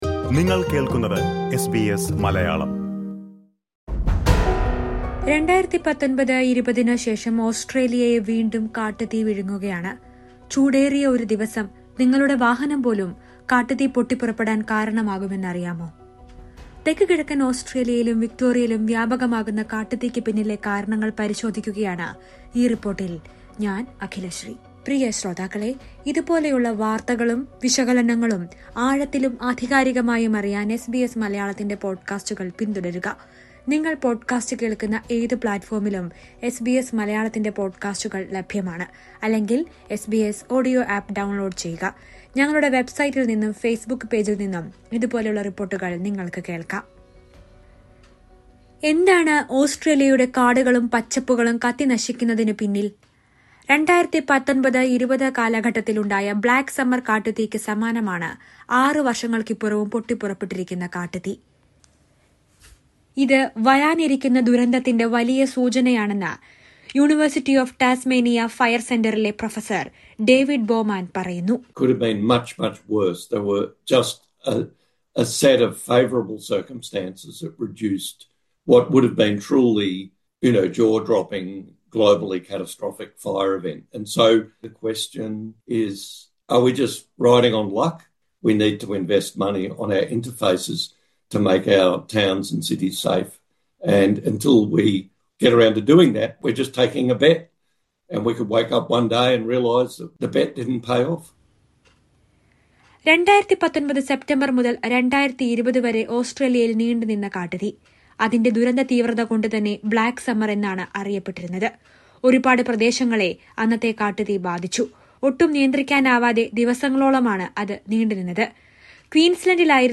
ഓസ്ട്രേലിയയിൽ കാട്ടുതീ വ്യാപകമാവുകയാണ്. കാട്ടുതീയ്ക്ക് പിന്നിലെ കാരണങ്ങൾ പരിശോധിക്കുകയാണ് ഈ റിപ്പോട്ടിൽ .